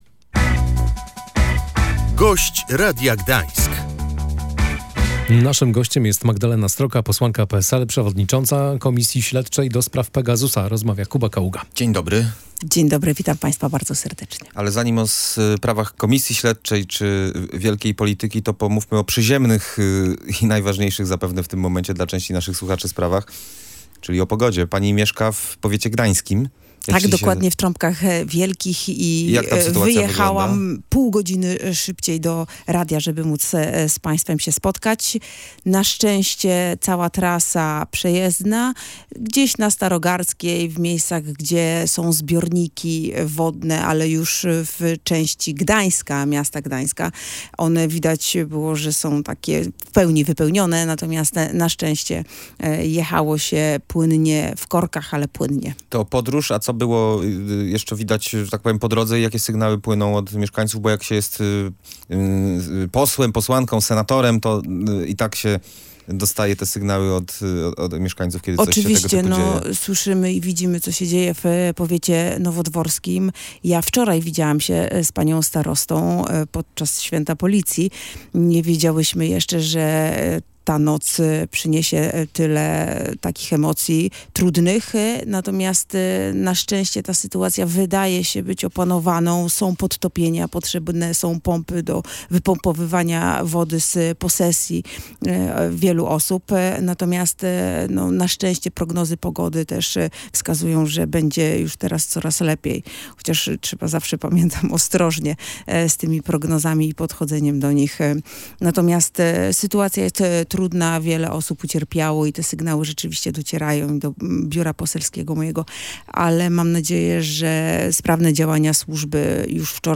Posłanka Polskiego Stronnictwa Ludowego Magdalena Sroka mówiła w Radiu Gdańsk, że czekają teraz na decyzję sądu.